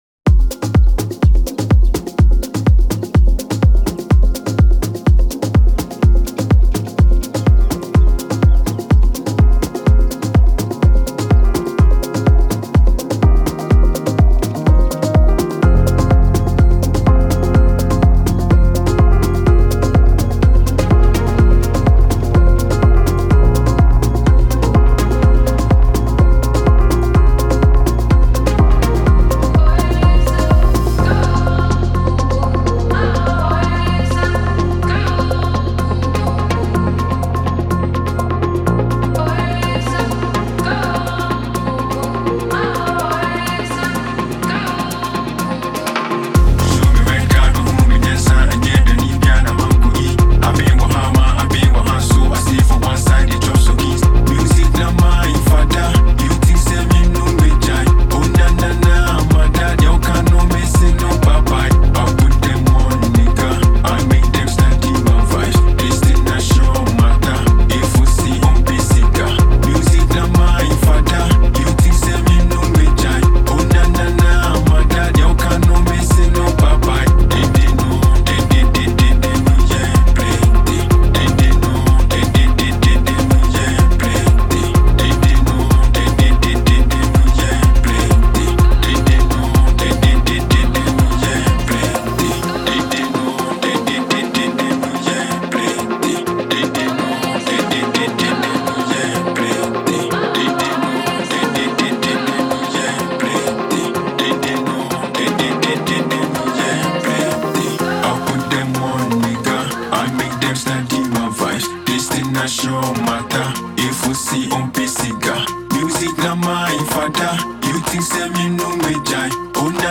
• Жанр: Electronic, House